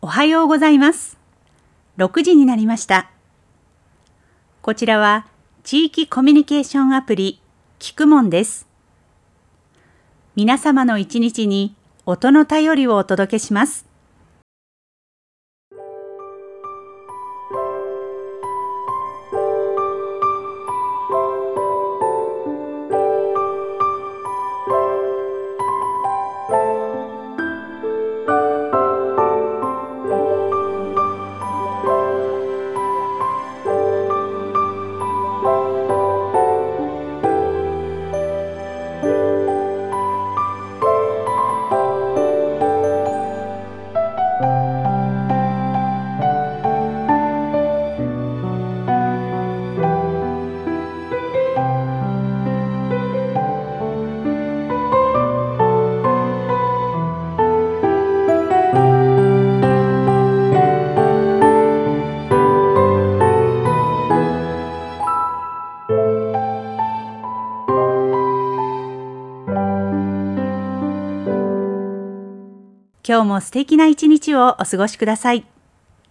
朝のごあいさつ [その他のファイル／13.94MB] 録音音声